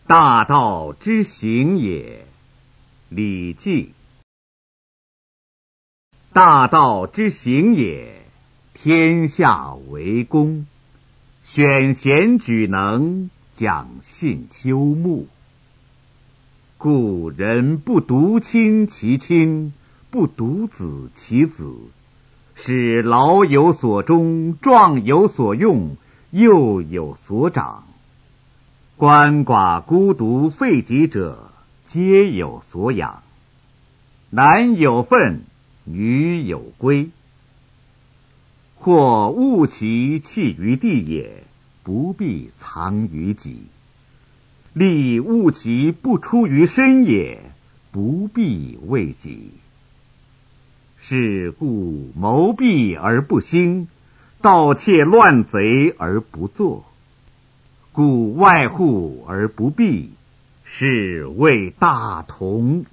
《大道之行也》课文朗读